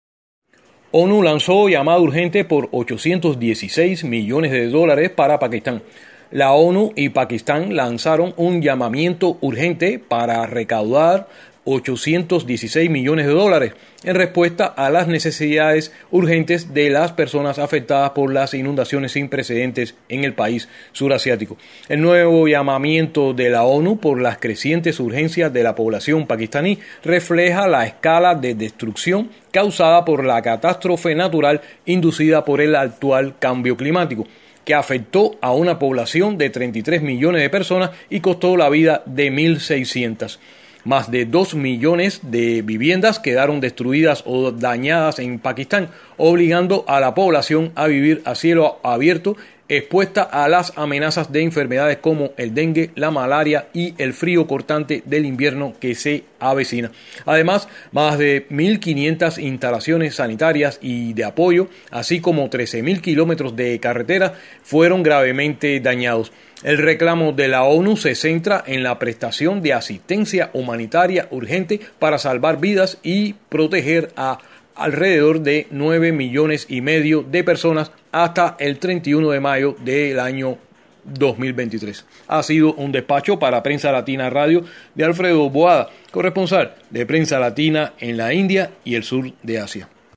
desde Islamabad